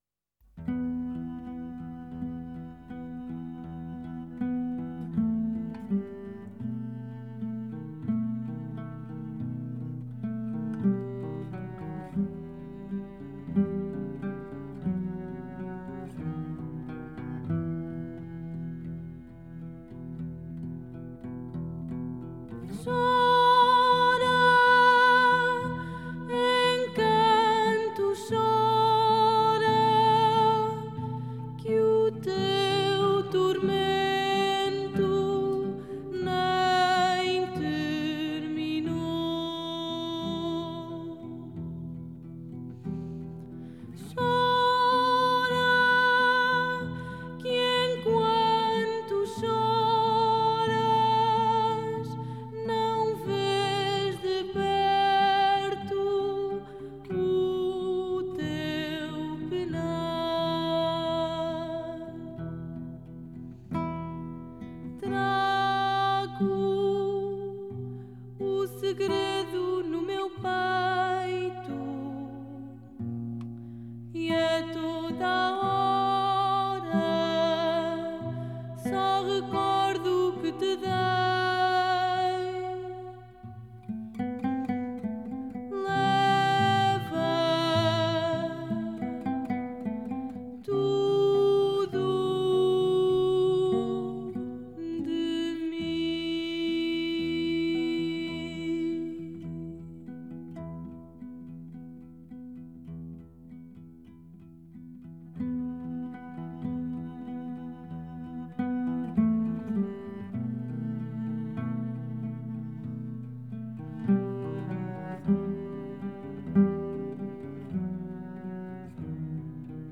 Genre: Fado, Ballad